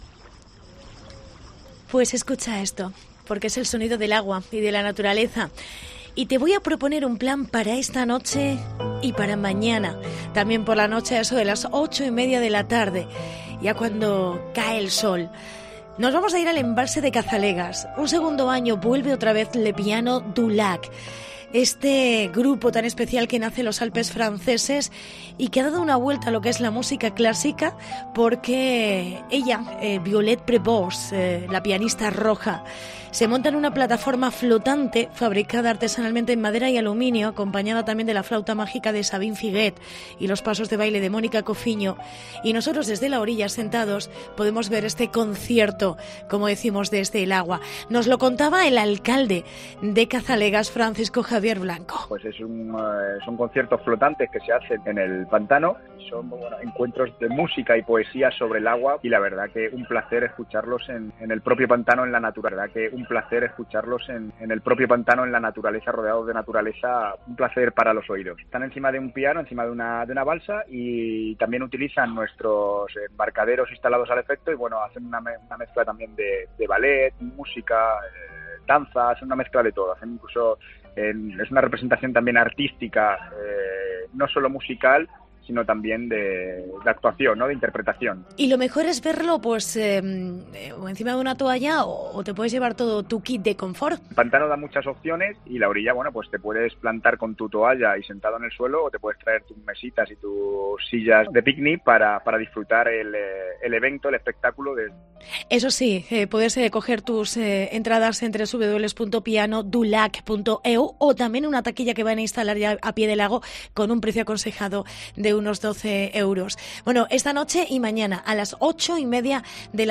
Reportaje Cazalegas